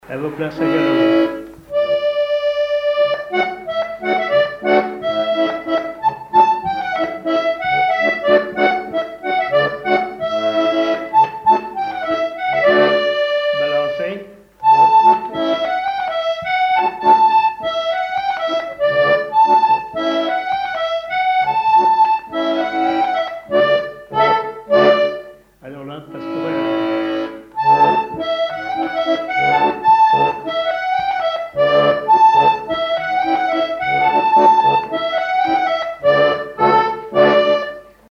Saint-Hilaire-de-Riez
accordéon diatonique
Pièce musicale inédite